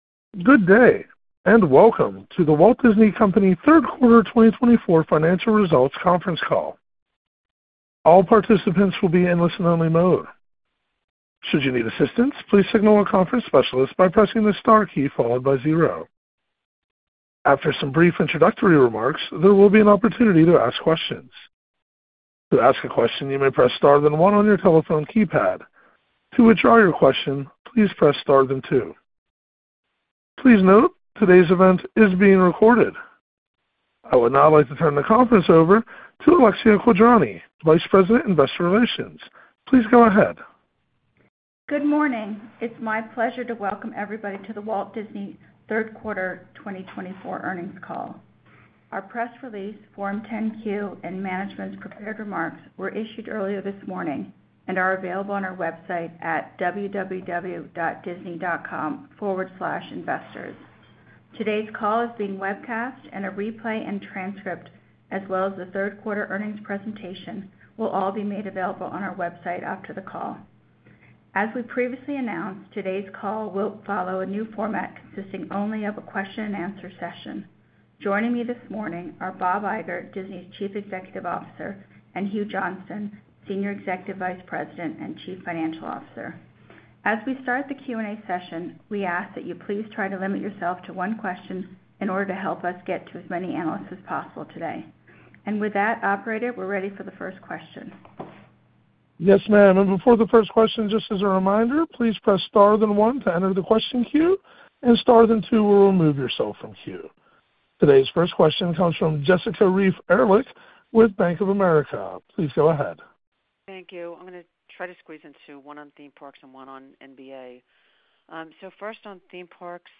Disney’s Q3 FY24 Earnings Results Webcast